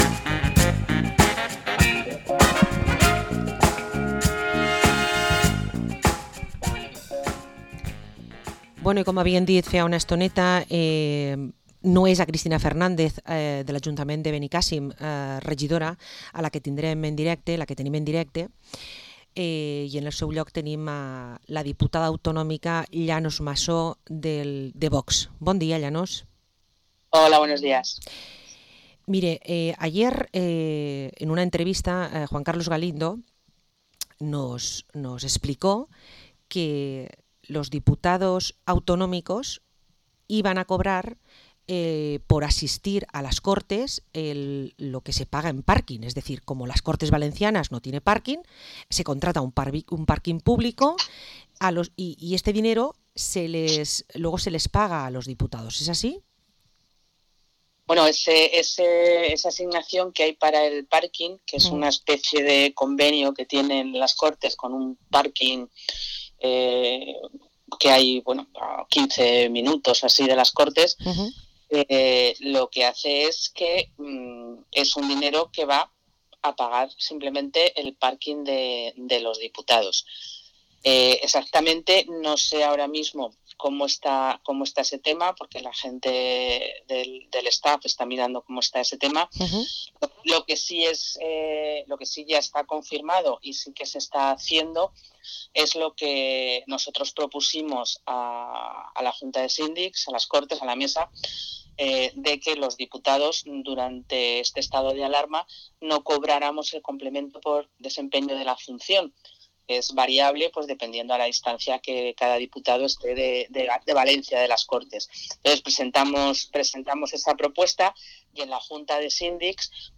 Entrevista a la diputada autonómica de VOX, Llanos Massó